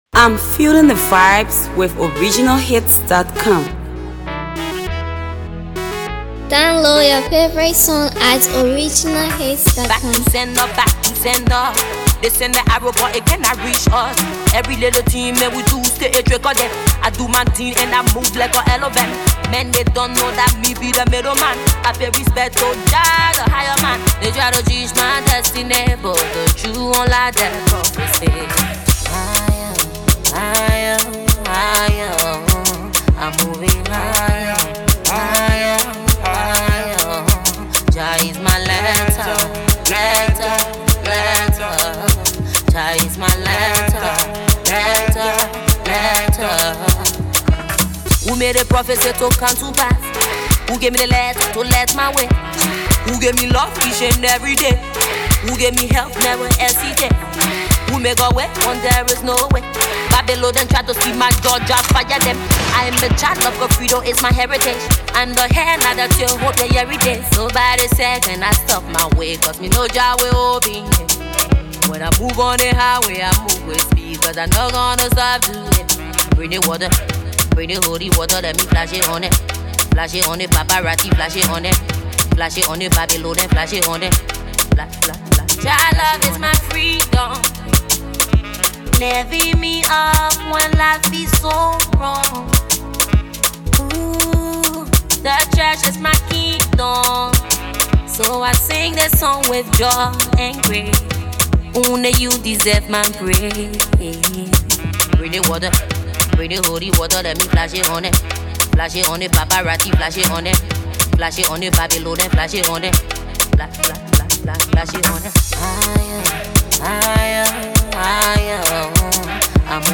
Talented Liberian songstress